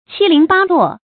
注音：ㄑㄧ ㄌㄧㄥˊ ㄅㄚ ㄌㄨㄛˋ
讀音讀法：